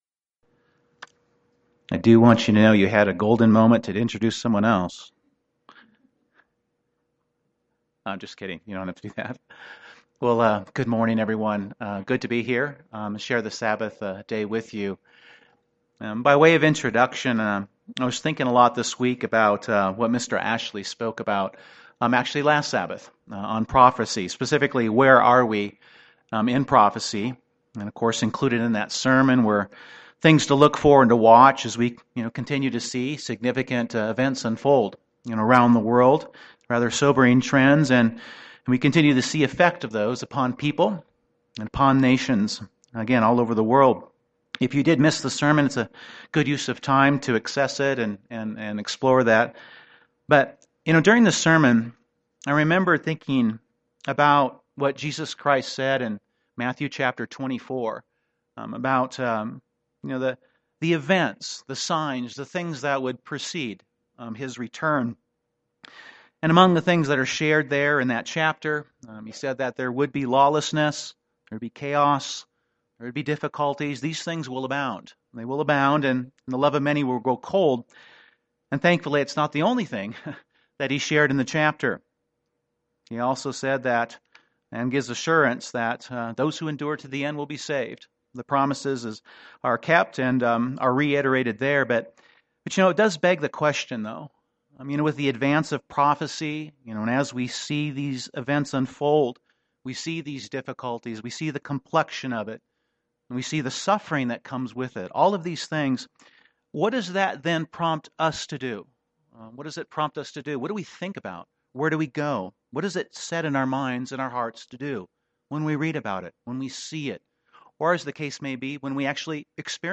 On the Day of Pentecost in AD 31, about 3,000 were baptized and added to the church. This sermon focuses on 4 things that Peter mentions they had in common and for which they set their minds and hearts to do thereafter (Acts 2:42); things that also apply to brethren today.
Given in Denver, CO